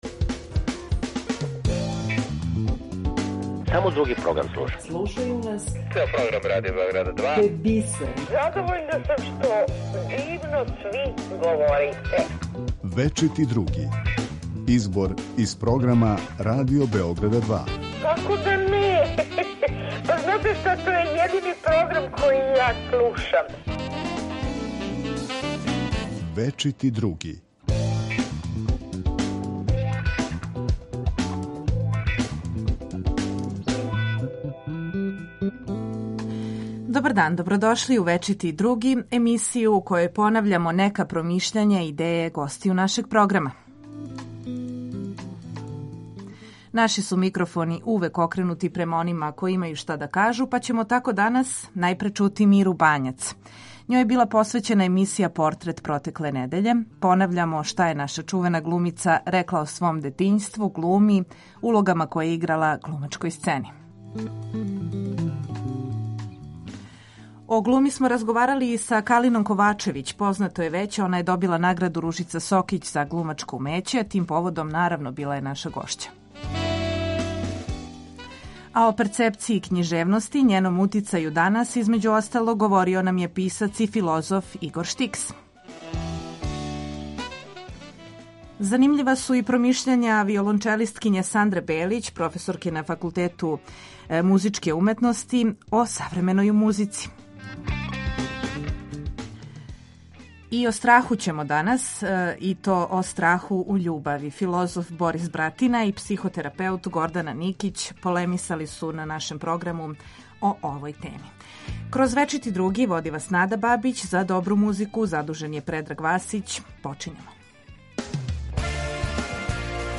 У Вечитом Другом чућемо данас нашу чувену глумицу Миру Бањац.